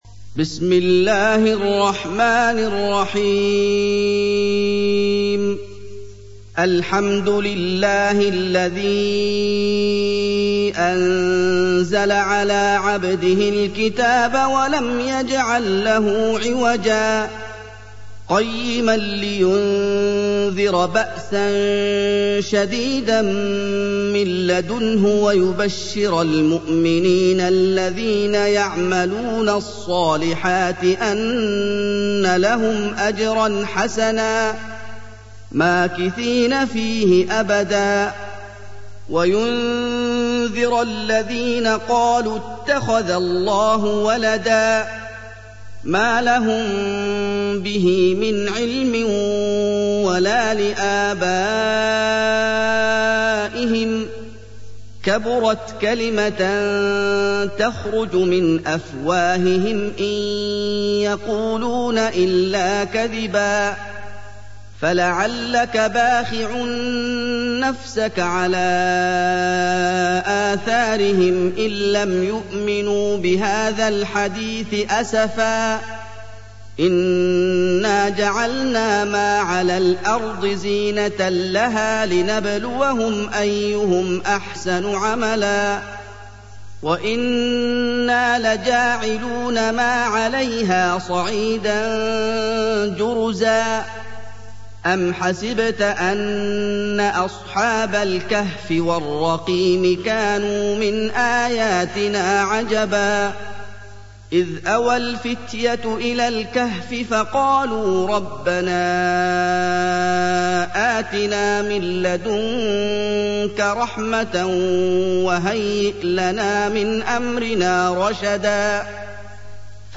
سُورَةُ الكَهۡفِ بصوت الشيخ محمد ايوب